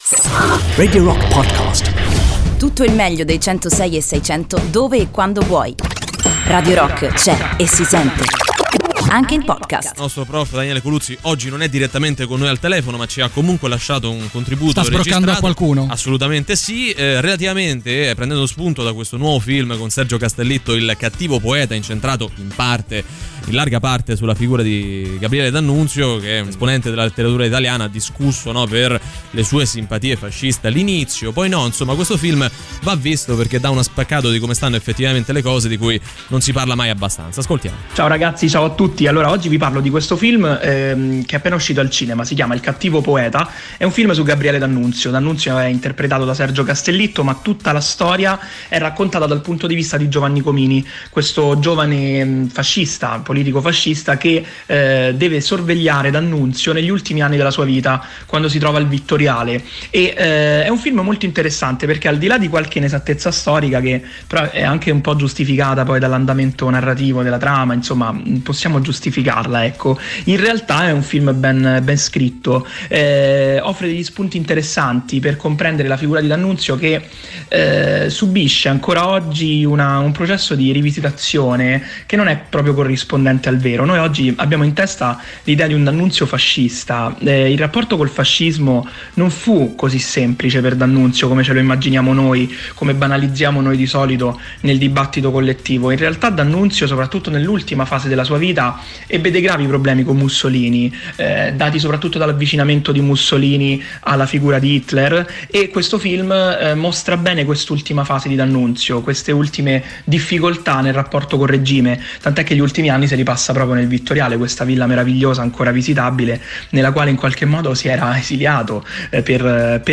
interviene in diretta su Radio Rock